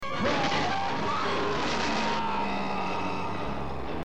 screams would be re-used a few years later by Unicron in the 1986 Transformers movie.